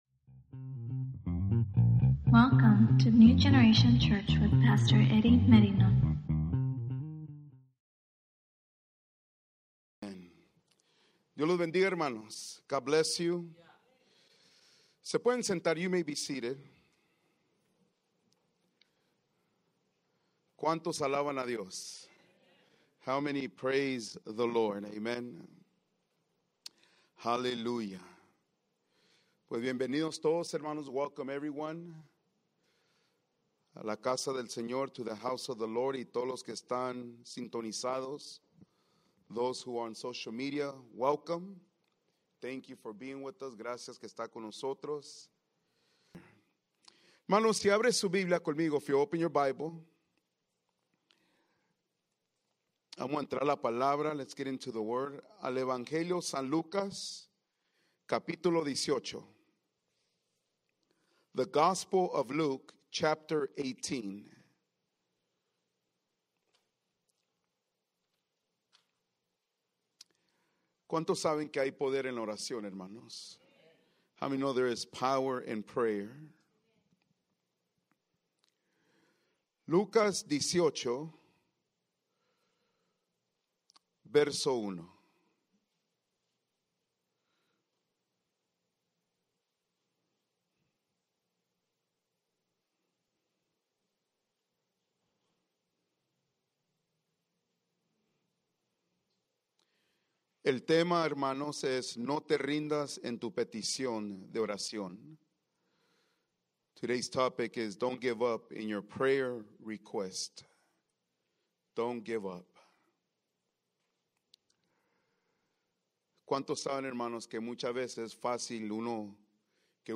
Sermons | New Generation Church